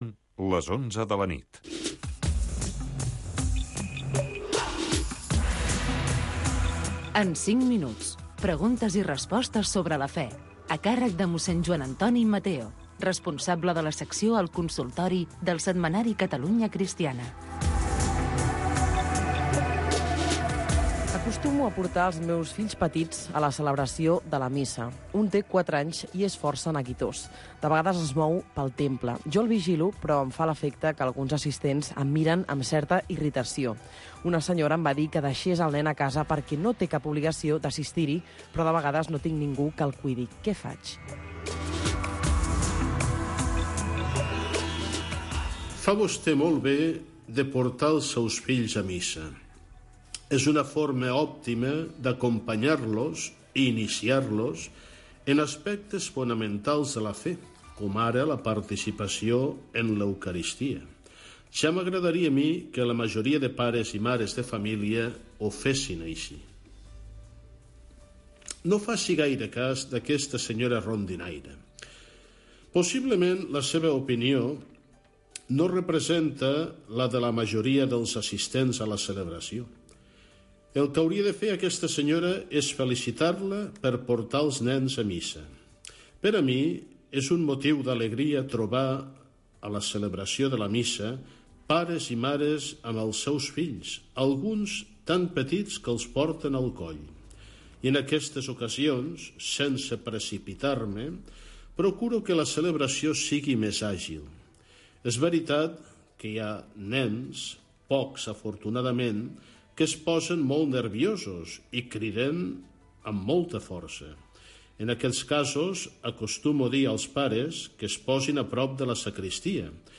Espai on els oients poden fer consultes de tipus religiós